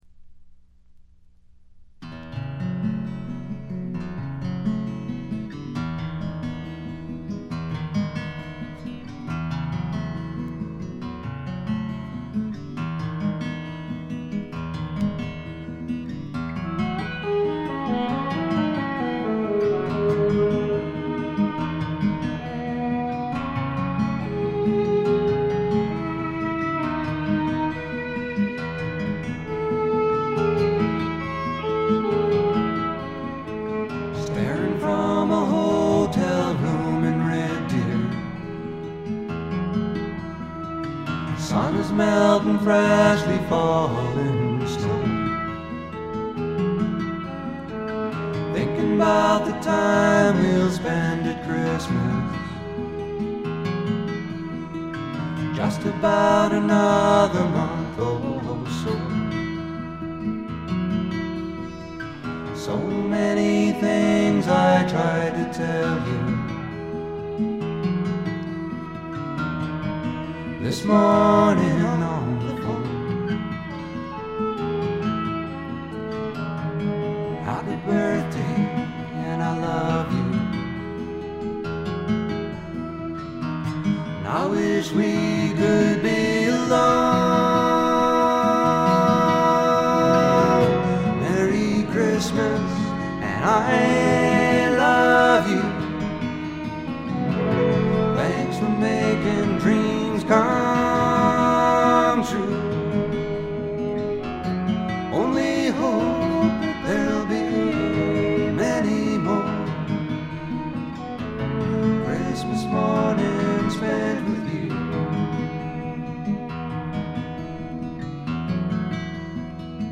軽微なチリプチほんの少し。
試聴曲は現品からの取り込み音源です。